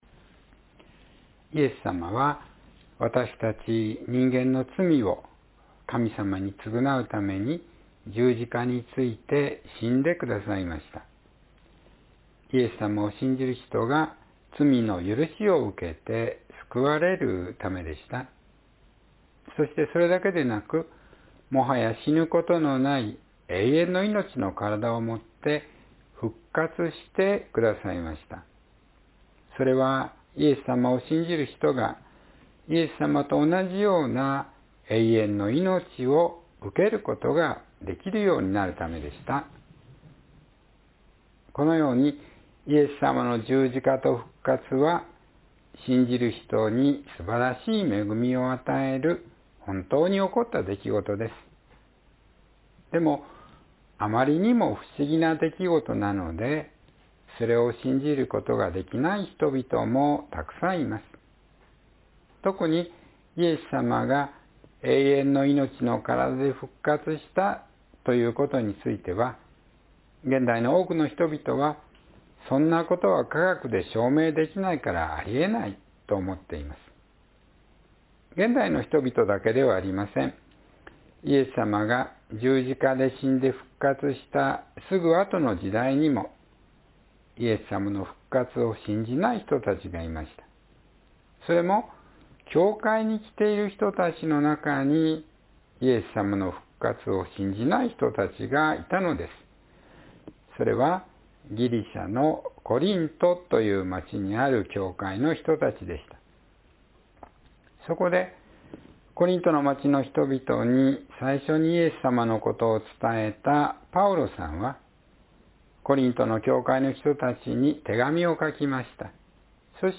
イエスさまの十字架と復活（2026年4月26日・子ども説教）